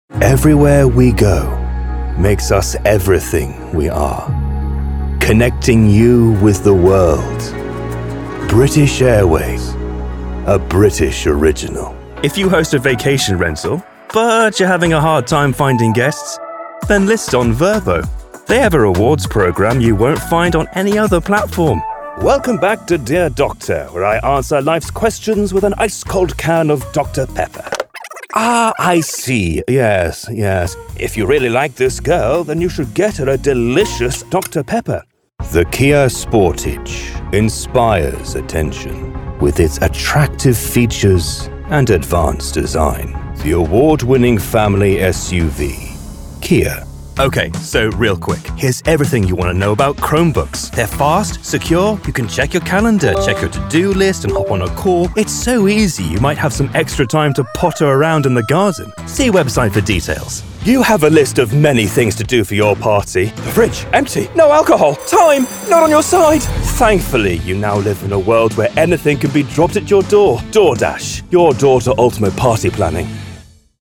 Engels (Brits)
Diep, Natuurlijk, Veelzijdig, Vertrouwd, Vriendelijk
Commercieel